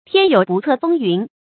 注音：ㄊㄧㄢ ㄧㄡˇ ㄅㄨˋ ㄘㄜˋ ㄈㄥ ㄧㄨㄣˊ
天有不測風云的讀法